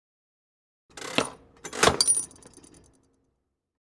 Metal Cut Press
SFX
yt_6FdauT8MjCg_metal_cut_press.mp3